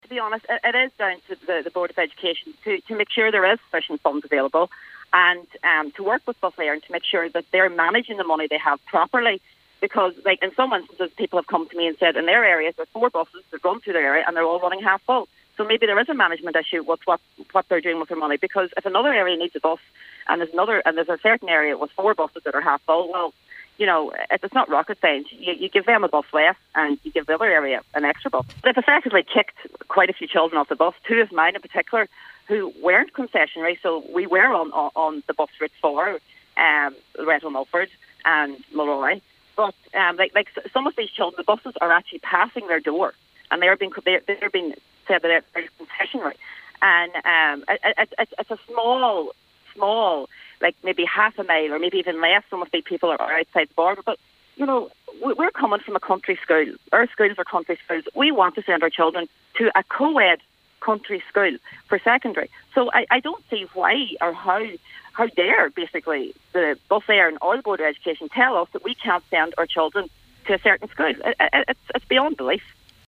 Concerned parent